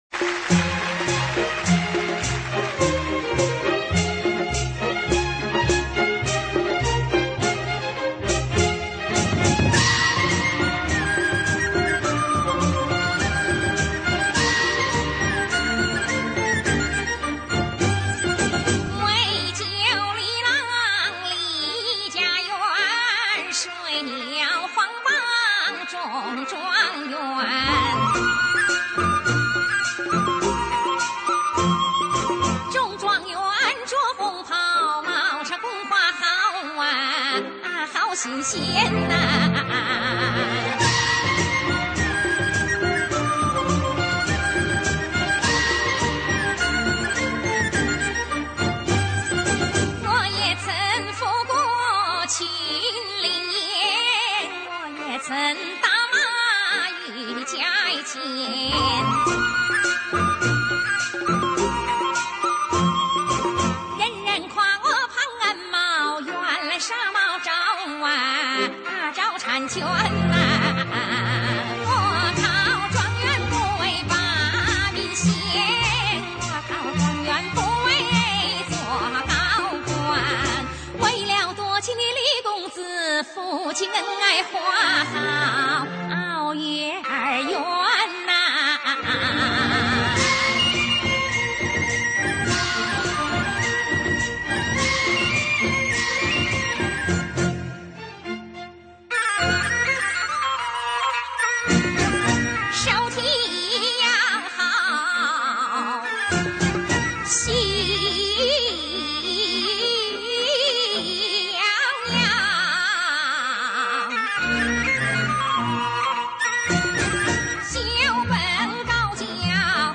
回复：[28/1/2013]黄梅戏《女驸马》全剧集 吴琼 主演